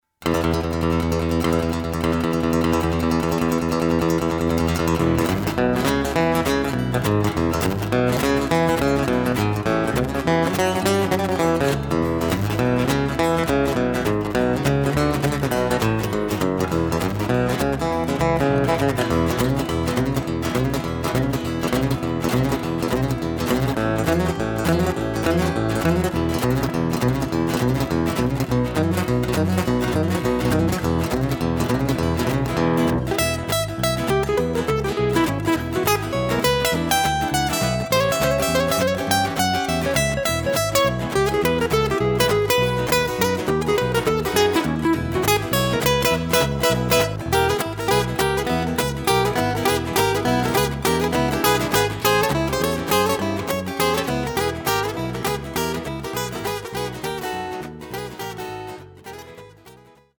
Chitarre e Basso